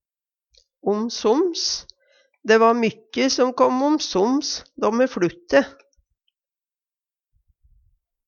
om soms - Numedalsmål (en-US)